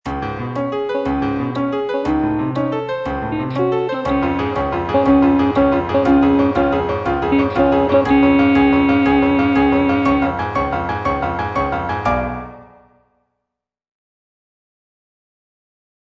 G. BIZET - CHOIRS FROM "CARMEN" En route, en route - Bass (Voice with metronome) Ads stop: auto-stop